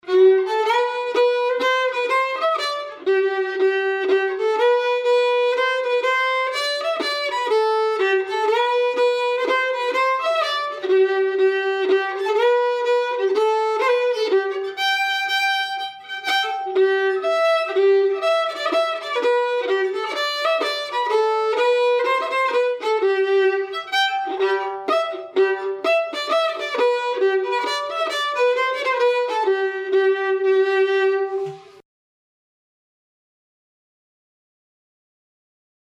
Fireside Fiddlers